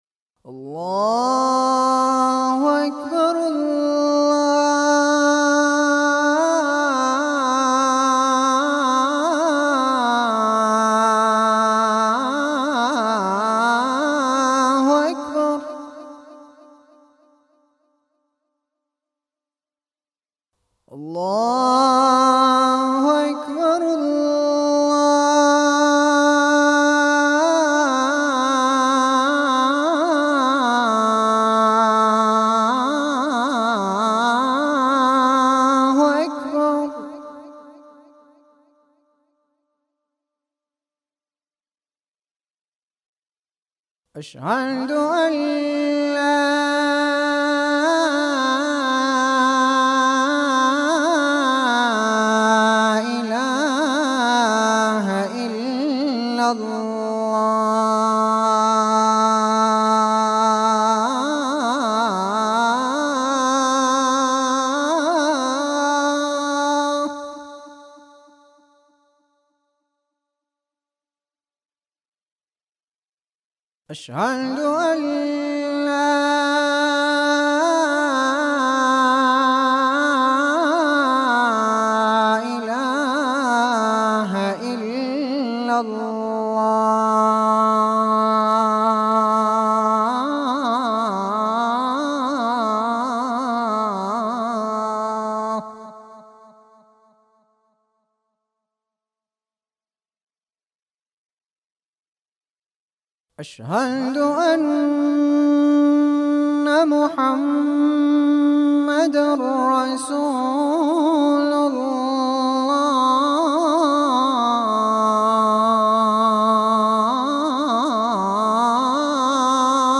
أناشيد ونغمات
عنوان المادة أذان - جـــــورجيــــأ